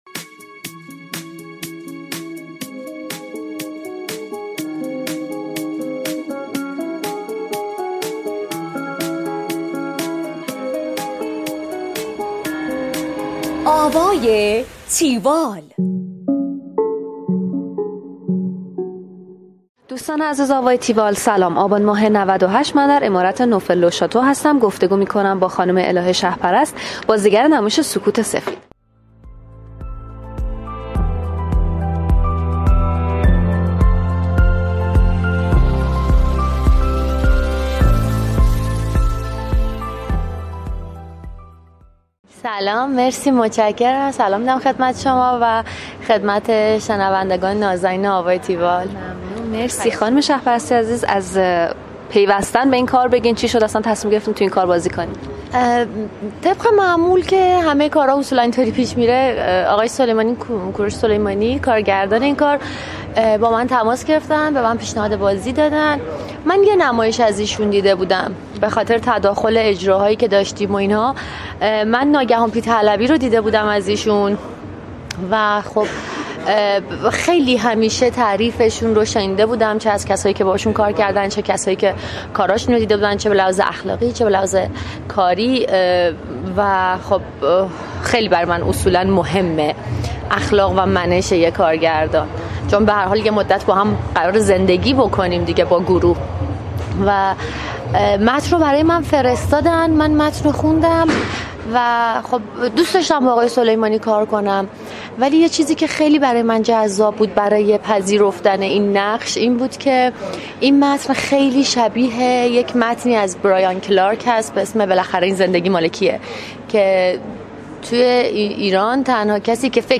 دانلود فایل صوتی گفتگوی تیوال